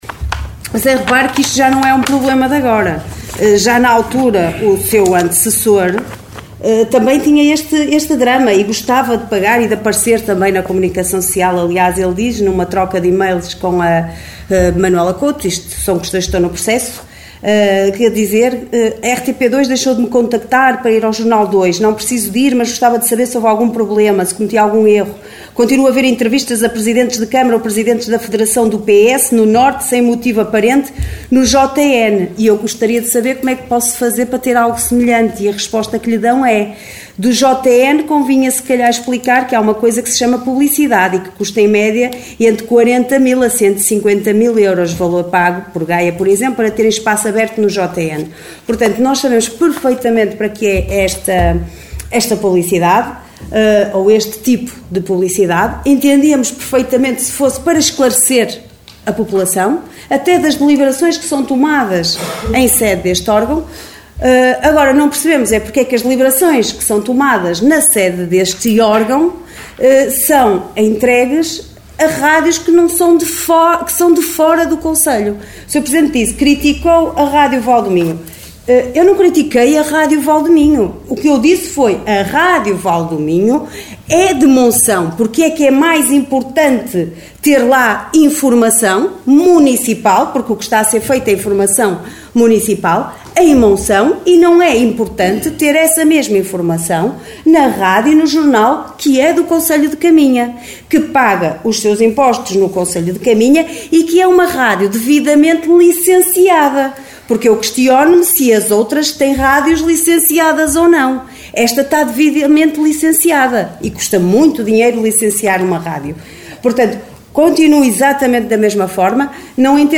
Excertos da última reunião de Câmara, realizada na passada quarta-feira no Salão Nobre dos Paços do Concelho, para contratação de 10 funcionários e atribuição de subsídios.